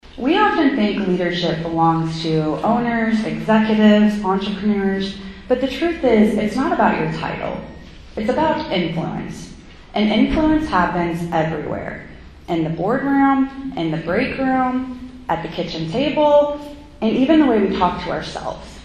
Farmington, Mo. (KFMO) - The Farmington Regional Chamber of Commerce held its monthly investor luncheon Thursday.